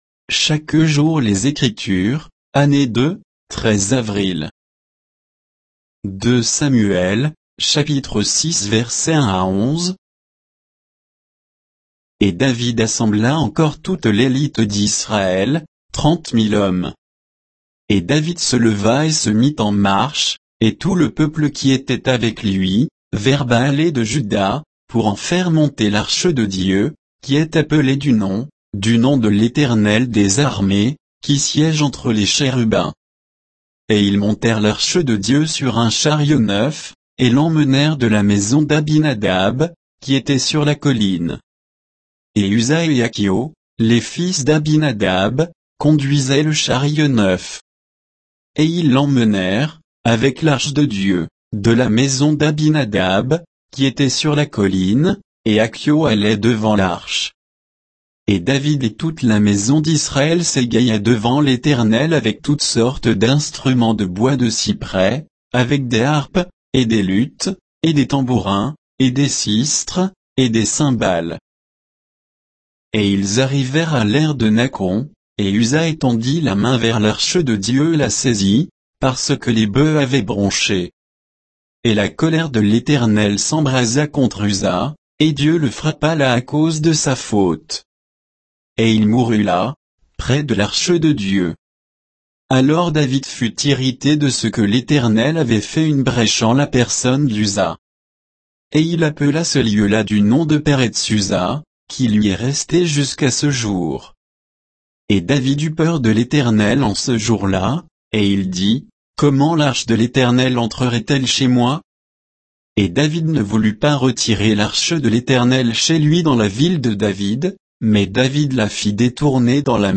Méditation quoditienne de Chaque jour les Écritures sur 2 Samuel 6